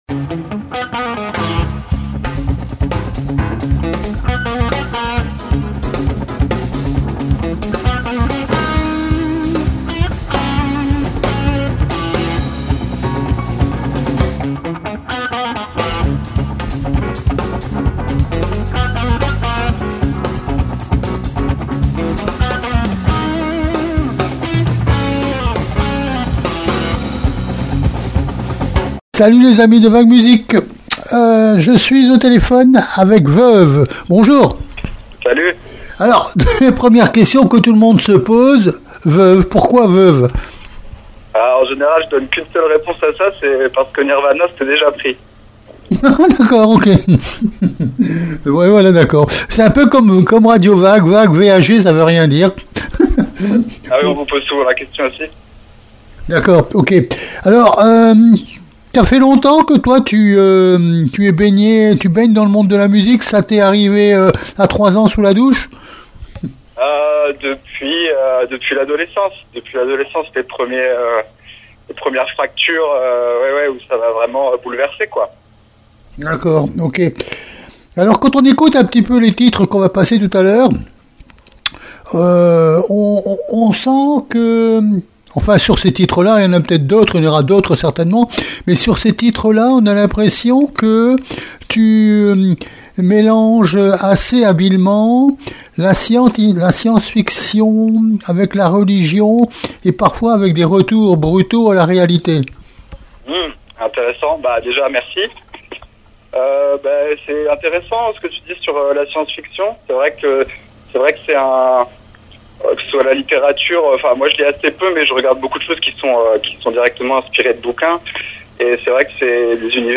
VEUVES-VAG MUSIC interview du 8 décembre 2025